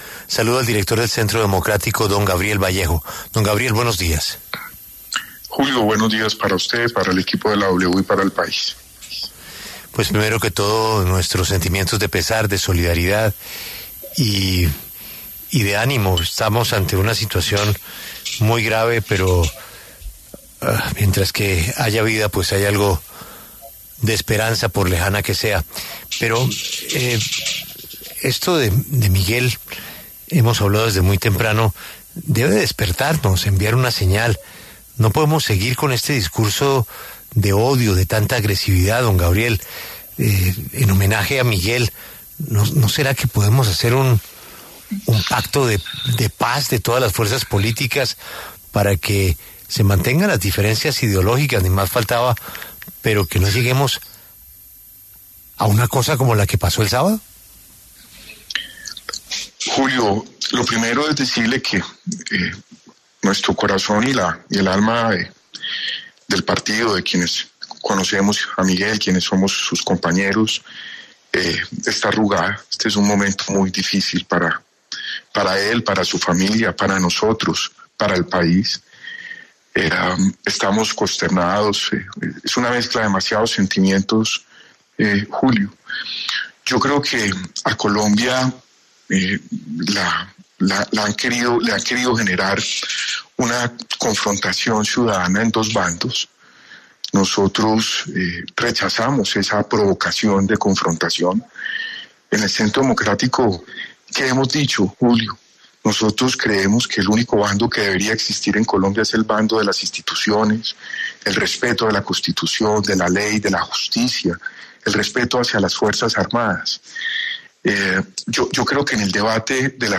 En diálogo con La W, Gabriel Vallejo, director del Centro Democrático, habló sobre el atentado contra Miguel Uribe Turbay el pasado 7 de junio.